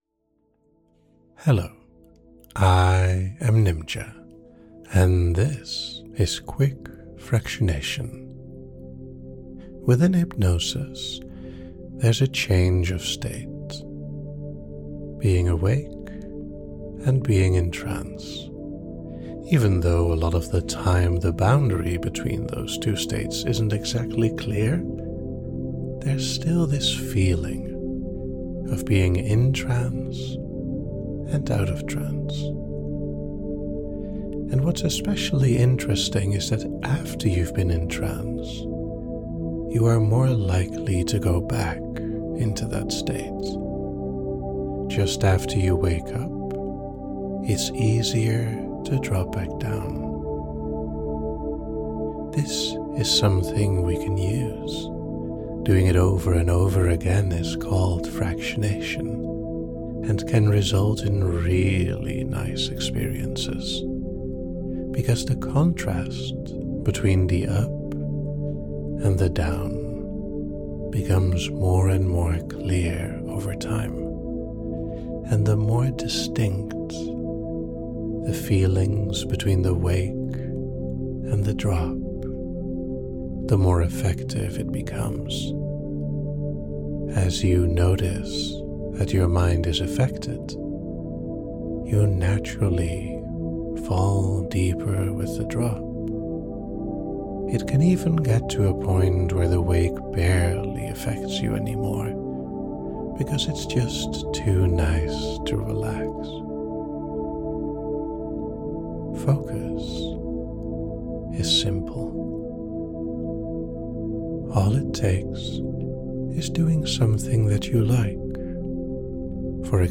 The listener experiences a gentle, rhythmic alternation between heightened awareness and deep relaxation, guided by the repeated cues of "wake" and "drop."
Instructions gently prompt them to focus, let go, and embrace the soothing descent, making each return to relaxation more profound than the last.